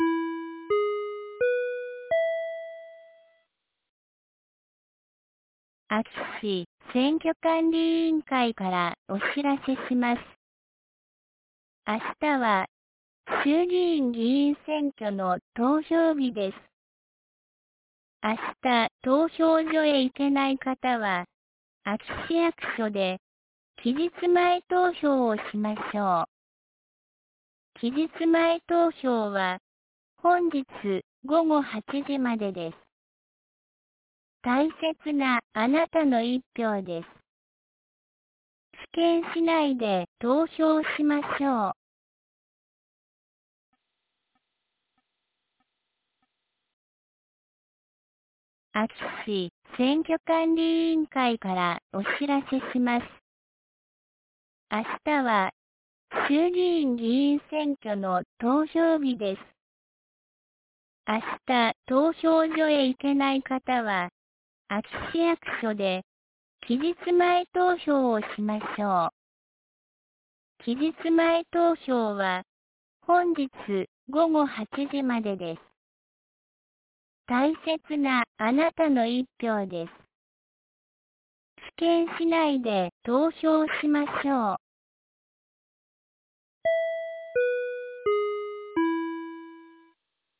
2024年10月26日 15時01分に、安芸市より全地区へ放送がありました。